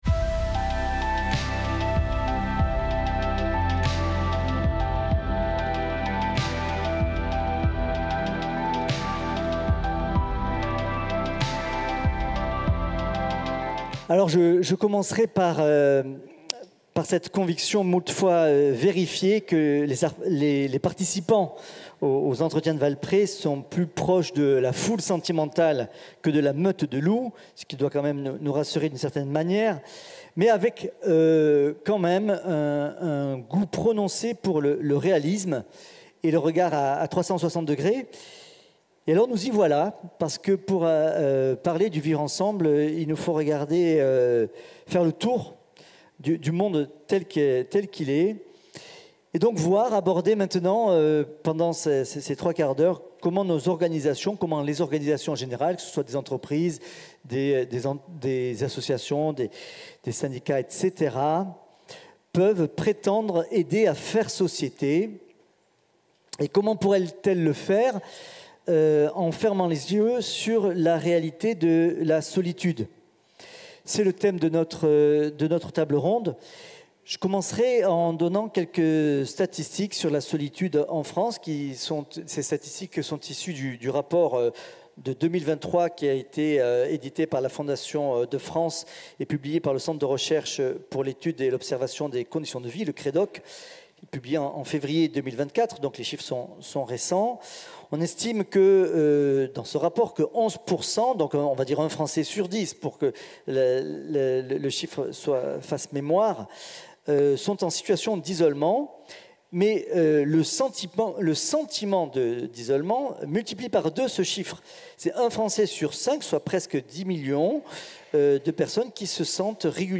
Enregistré le 15 novembre 2024 à Valpré (Lyon).